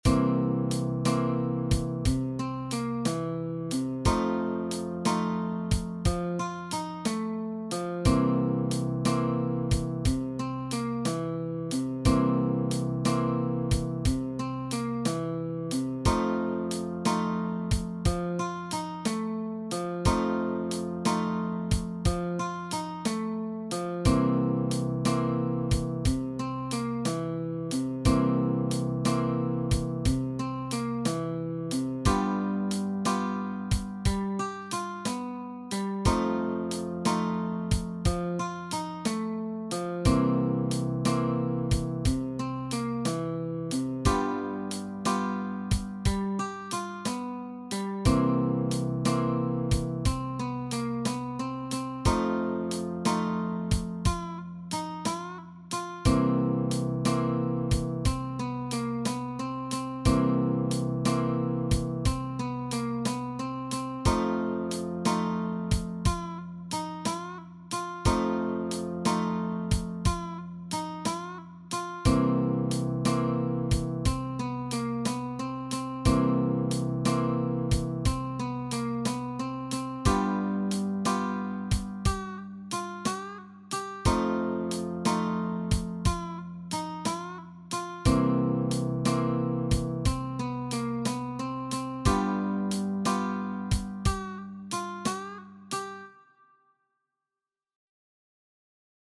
Blues mit 7-er Akkorden Drifting in C-Dur – ein Blues-typischer 12-Takter mit 24 Takten – eignet sich gut für das Spiel mit den Fingern auf einer akustischen Gitarre.
Drifting in C-Dur – ein Blues-typischer 12-Takter mit 24 Takten – eignet sich gut für das Spiel mit den Fingern auf einer akustischen Gitarre.
Die Akzente werden durch die Kombination von Triolen und dem Shuffle gesetzt.
G.7 Audiodatei aus MuseScore exportiert mit dem Klang der Nylon String Guitar.